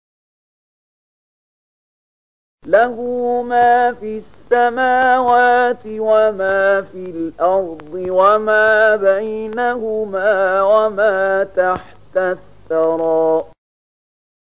020006 Surat Thaahaa ayat 6 dengan bacaan murattal ayat oleh Syaikh Mahmud Khalilil Hushariy: